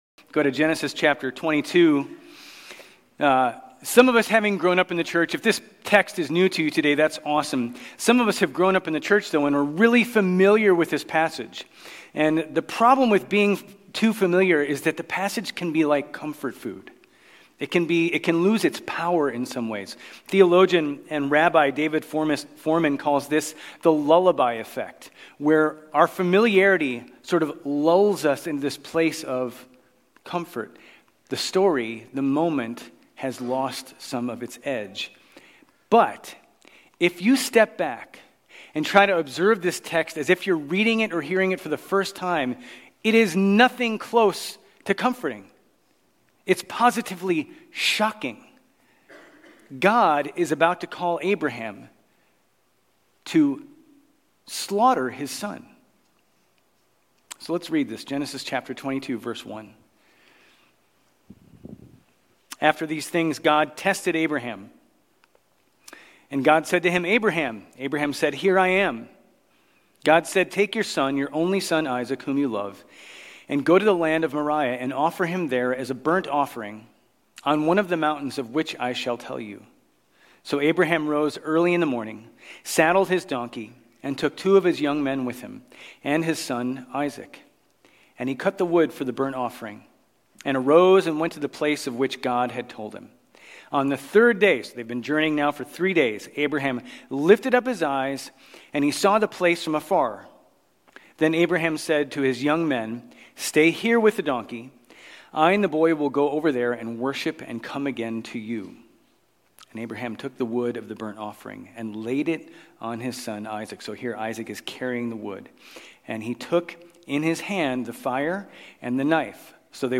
Grace Community Church Old Jacksonville Campus Sermons Gen 22 - Sacrifice of Isaac Oct 28 2024 | 00:35:12 1x 00:00 / 00:35:12 Subscribe Share RSS Feed Share Link Embed